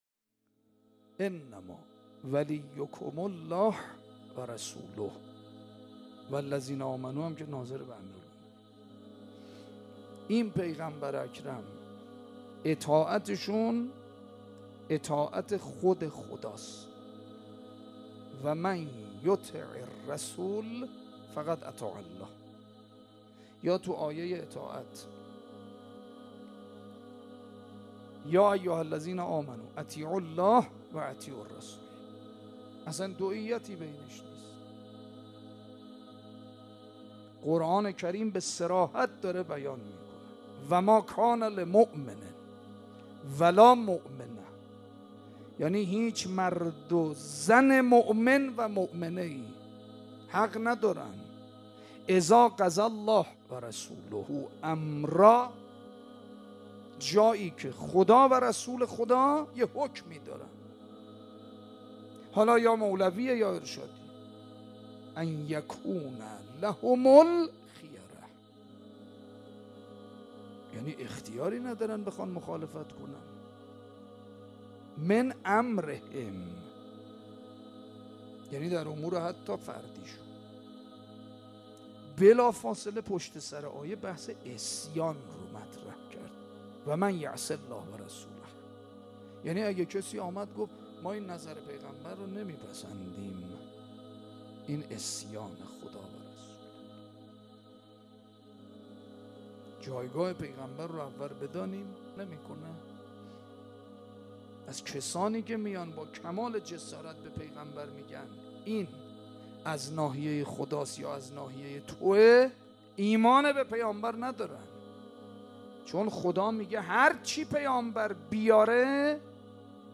سخنرانی در رابطه با پیامبر اکرم